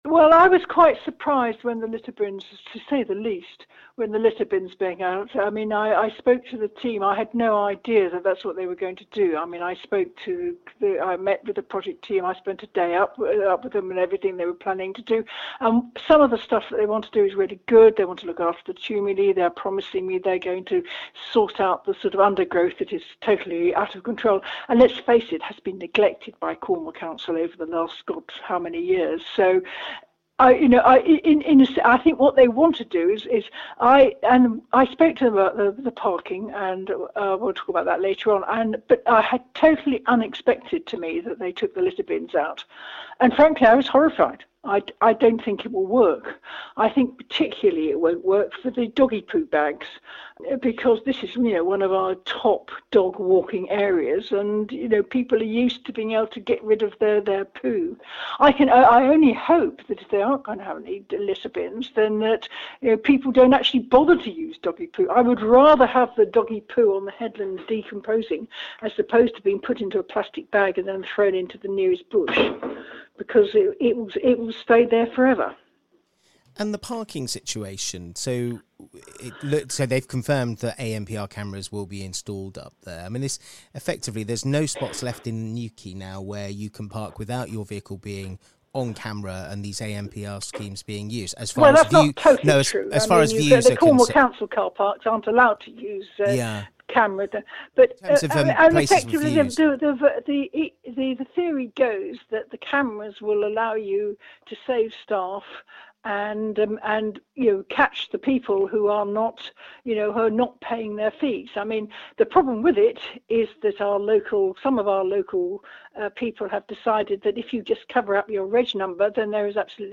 PRESS PLAY to hear more from Joanna Kenny, Cornwall Councillor for Newquay Central and Pentire...
Note: This interview was recorded before the revised statement from Cornwall Heritage Trust